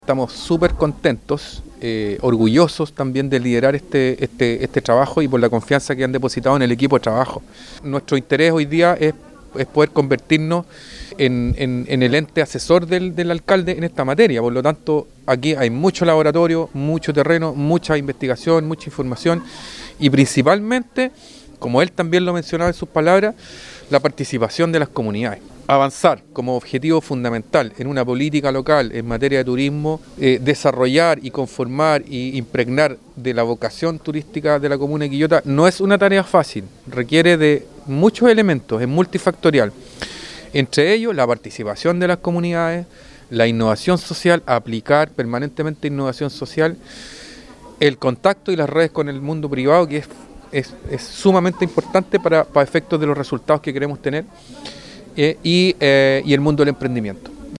La actividad tuvo lugar en el local de San Pedro de Putupur, Restaurant Promo 385, y contó con la presencia de diferentes autoridades del turismo regional